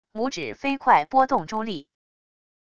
拇指飞快拨动珠粒wav音频